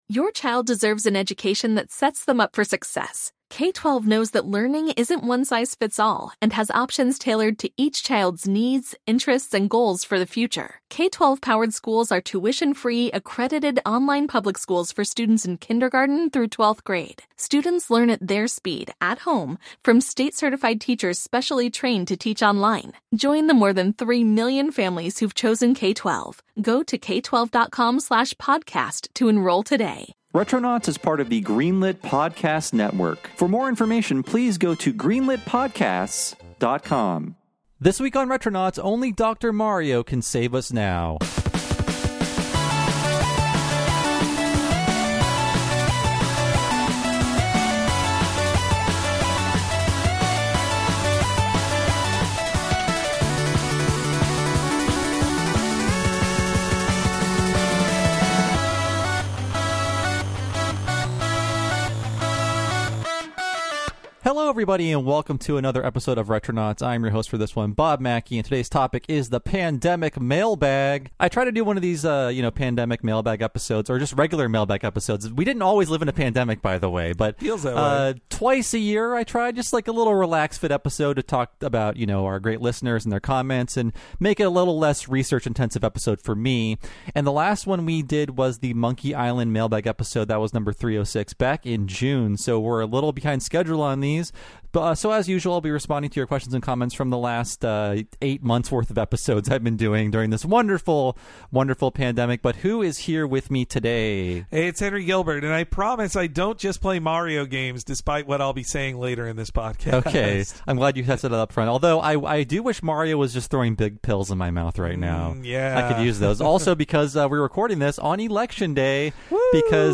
Since we recorded this one on Election Day, it's delightfully dated instead of just being dated!